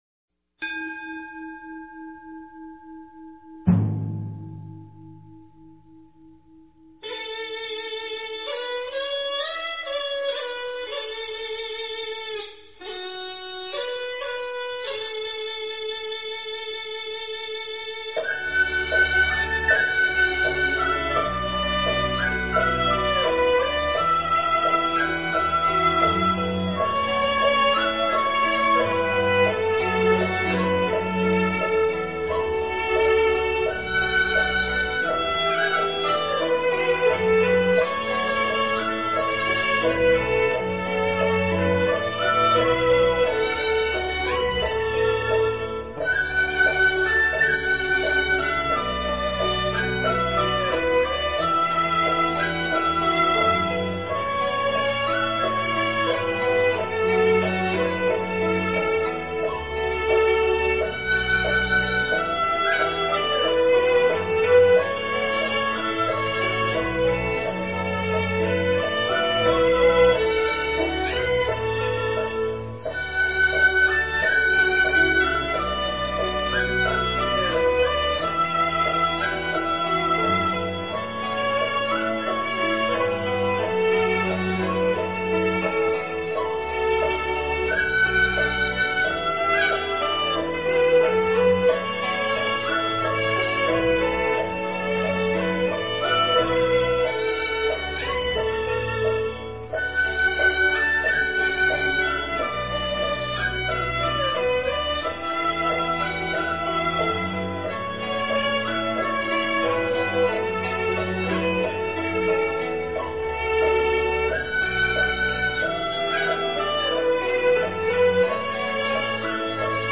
妙音偈--僧团 经忏 妙音偈--僧团 点我： 标签: 佛音 经忏 佛教音乐 返回列表 上一篇： 早课--辽宁万佛禅寺 下一篇： 观世音菩萨白佛言--佛光山梵呗团 相关文章 早课--福严佛学院 早课--福严佛学院...